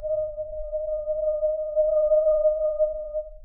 Percussion
alien1_pp.wav